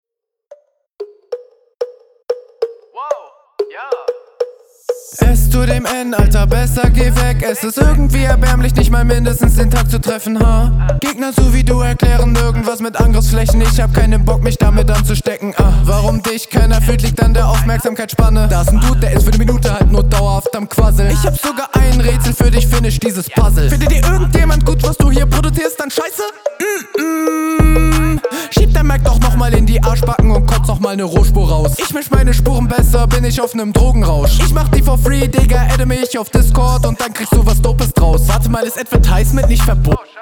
Soundquality und Attitüde kommen gut. Flow manchmal bisschen weird, aber teilweise auch sehr cool.